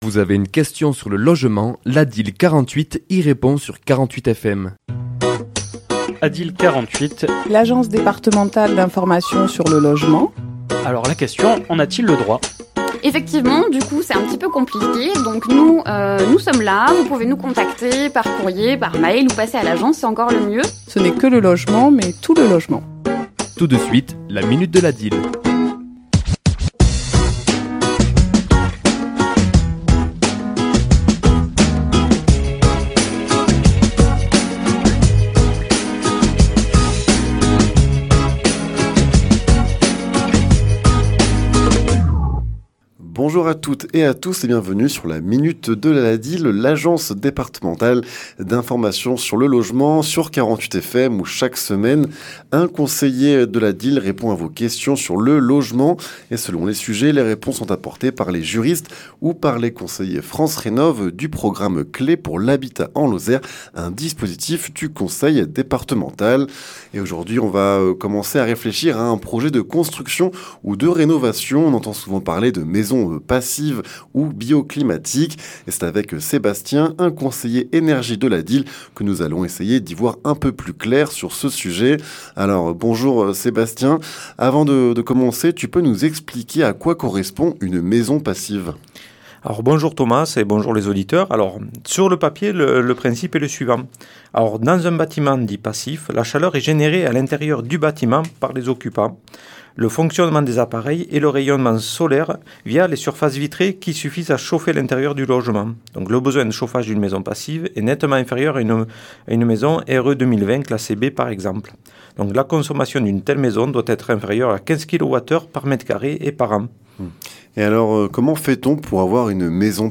Chronique diffusée le mardi 30 décembre à 11h et 17h10